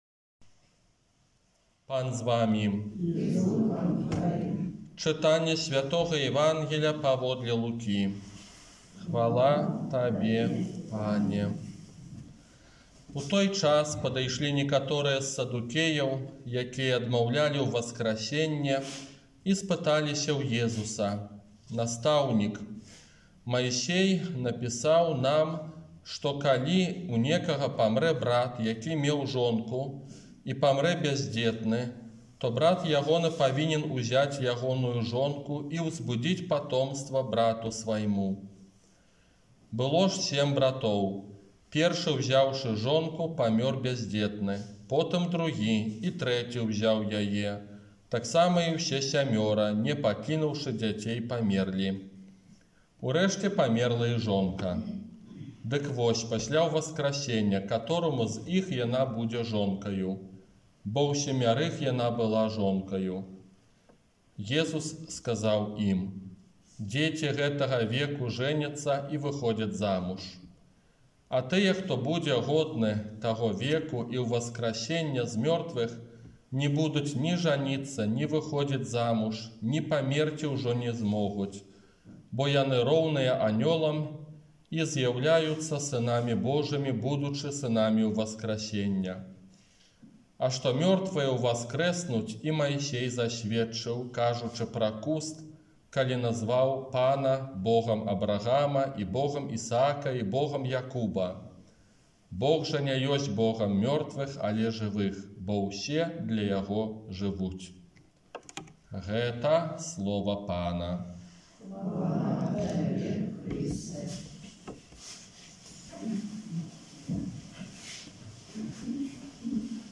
ОРША - ПАРАФІЯ СВЯТОГА ЯЗЭПА
Казанне на трыццаць другую звычайную нядзелю